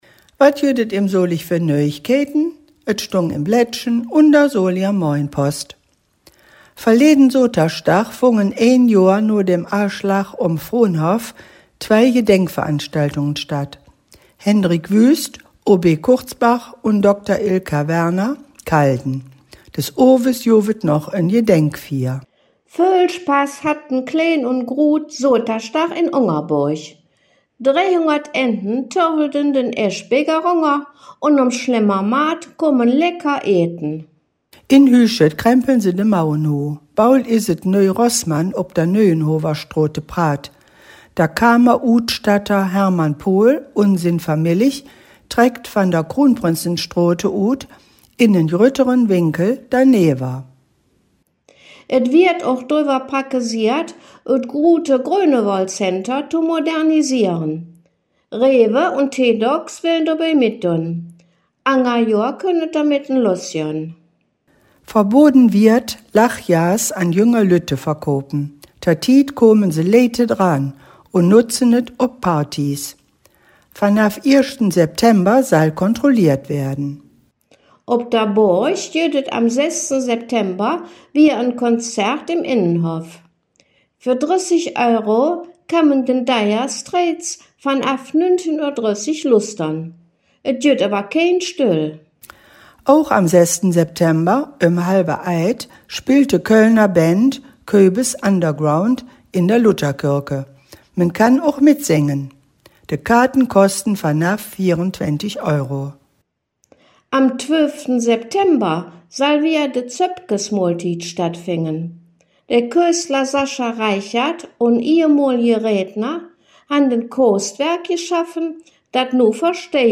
solinger-platt-nachrichten-does-weeke-em-solig-25-35.mp3